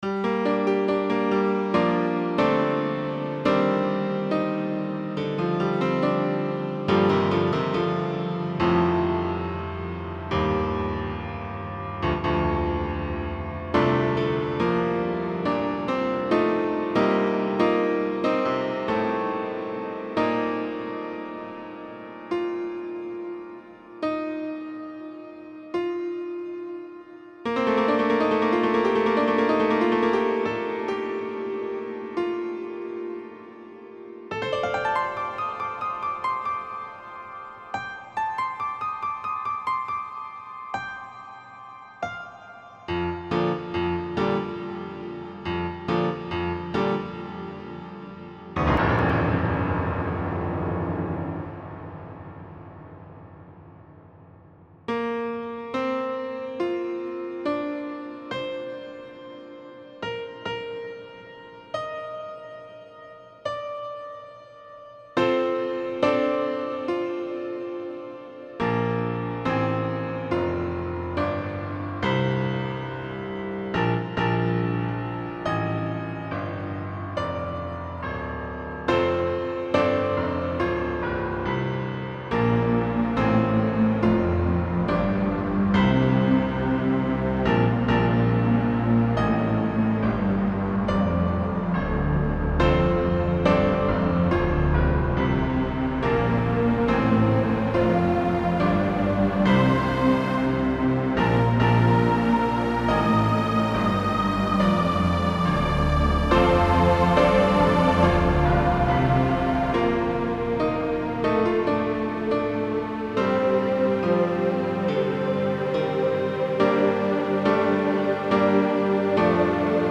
Description: This recording was sequenced in FruityLoops 3.4, and uses an older VST synthesizer for strings, and IK Multimedia's Sampletank for the piano. Percussion was done with various samples in my collection.